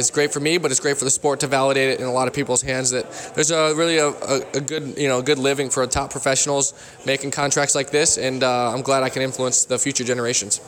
‘Validation;’ Dynamic Discs ownership and newest team members reflect on magnitude of recent signings during press conference Tuesday